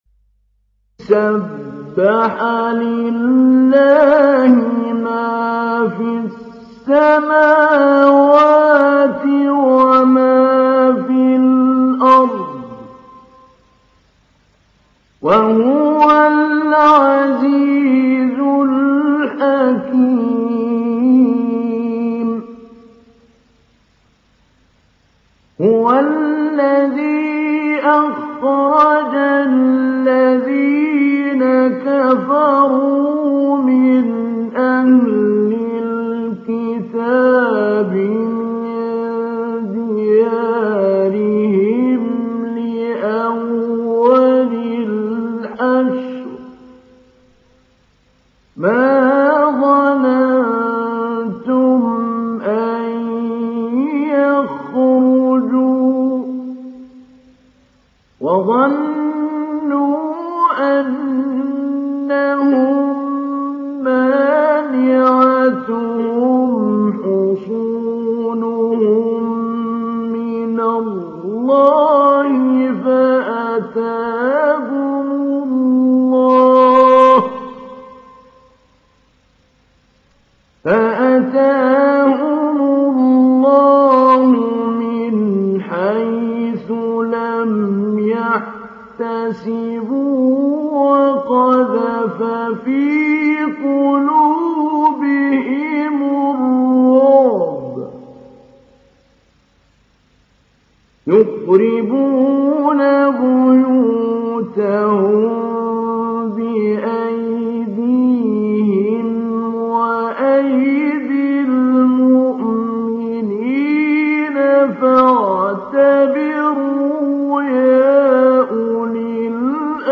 Télécharger Sourate Al Hashr Mahmoud Ali Albanna Mujawwad